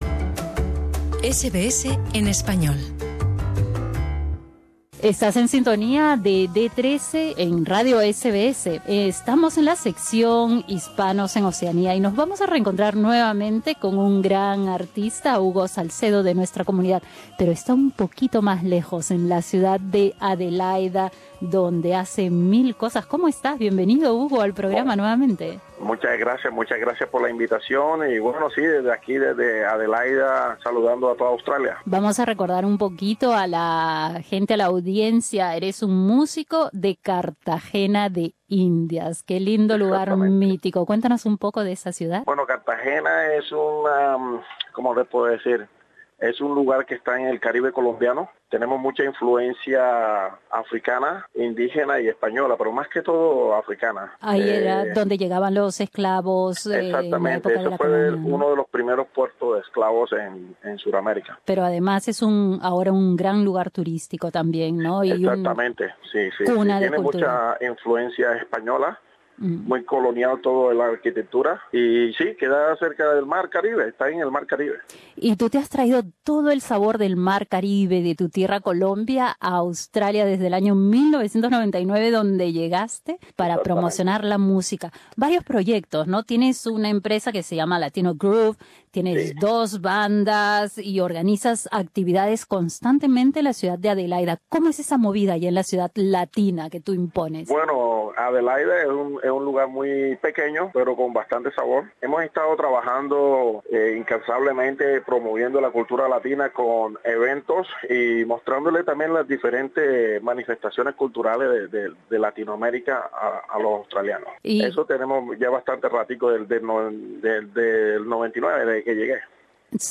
También nos cuenta que ha creado una nueva canción titulada La salsa de Australia Escucha su entrevista en el podcast.